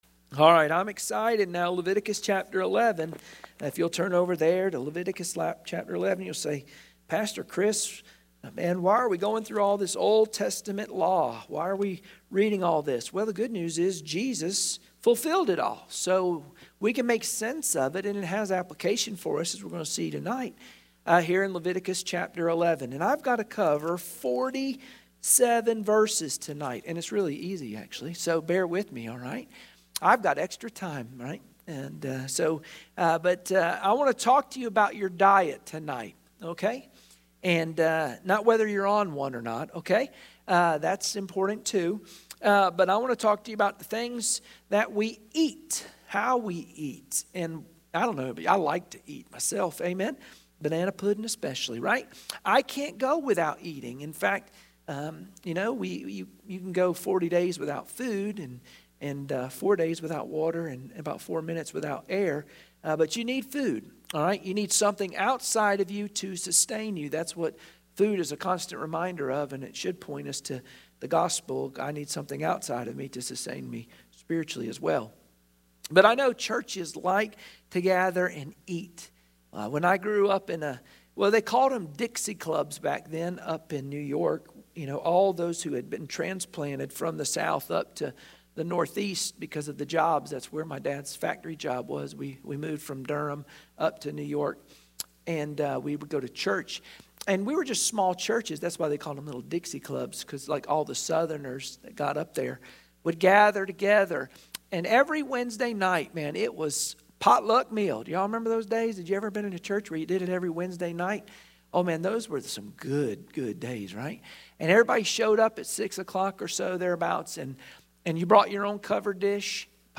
Wednesday Prayer Mtg Passage: Leviticus 11 Service Type: Wednesday Prayer Meeting Share this